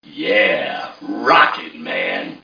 1 channel
mission_voice_fmad005.mp3